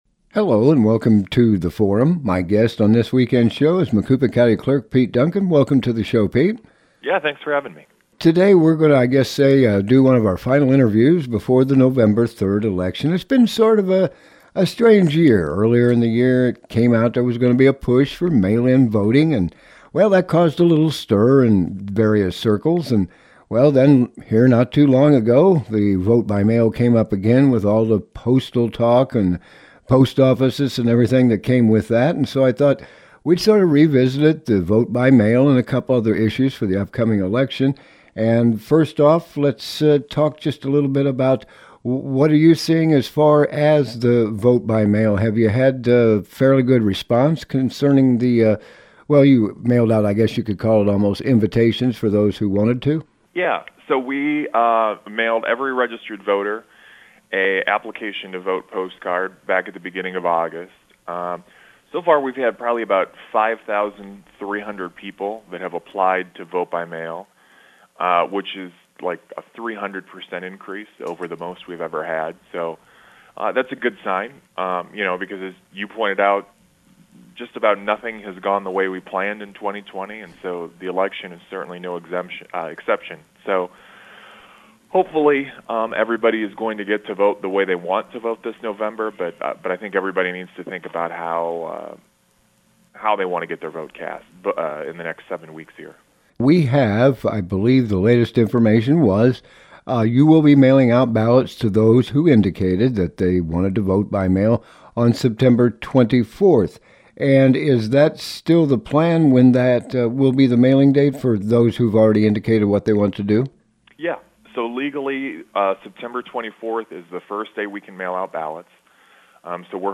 Guest: Macoupin County Clerk Pete Duncan